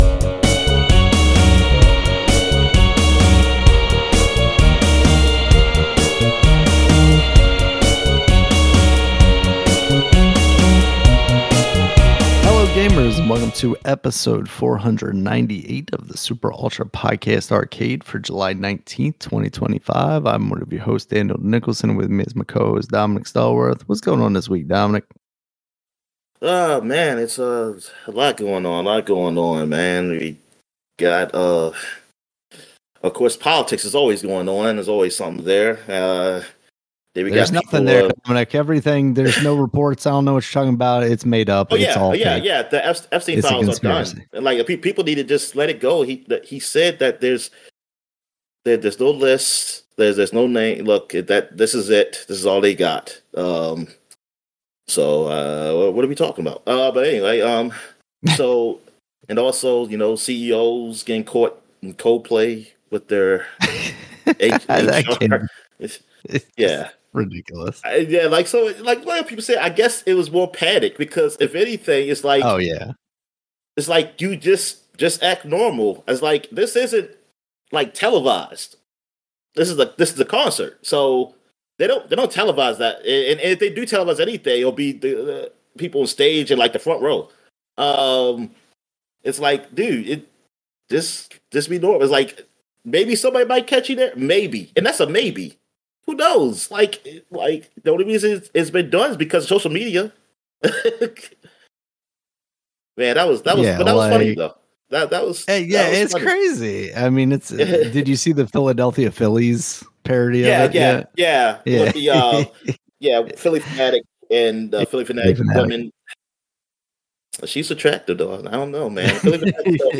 A group of friends take turns discussing the ins and outs of the gaming world and their personal lives.